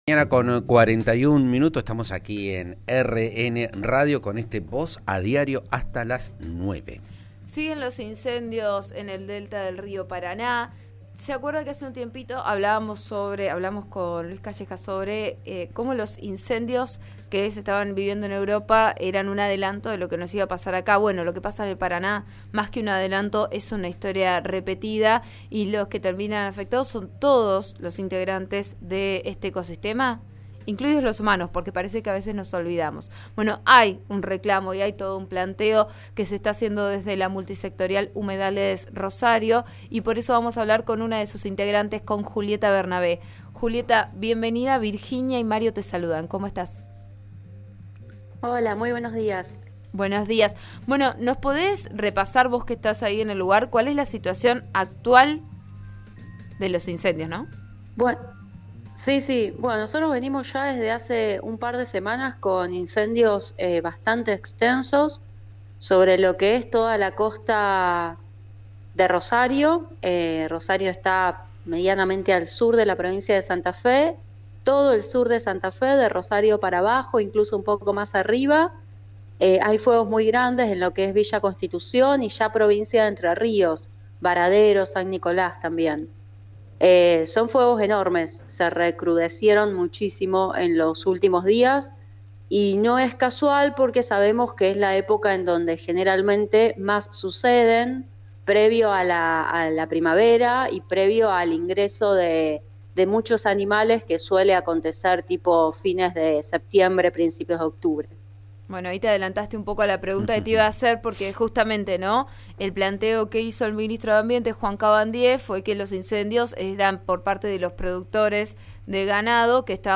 En declaraciones radiales realizadas esta mañana, el ministro también afirmó que 'nadie duda que el sector agropecuario es estratégico, nuestra idea no es estigmatizar o generar un encono, pero no pueden hacer lo que están haciendo'.